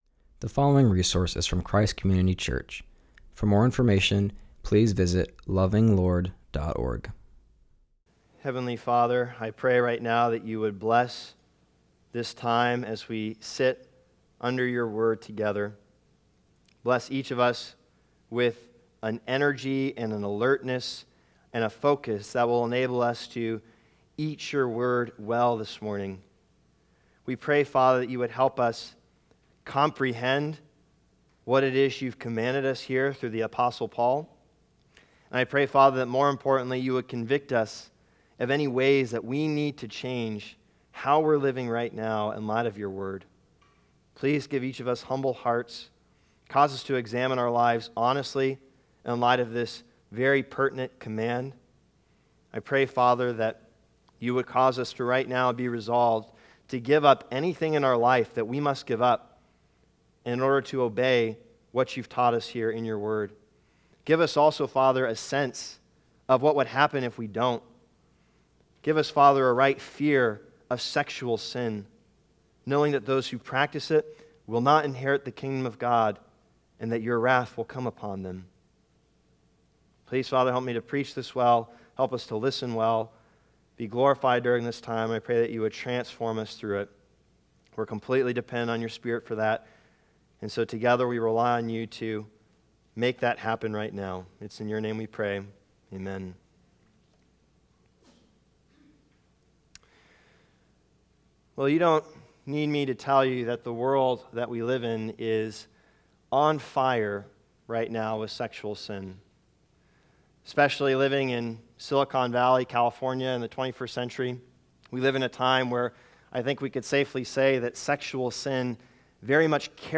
preaches from Ephesians 5:3-6.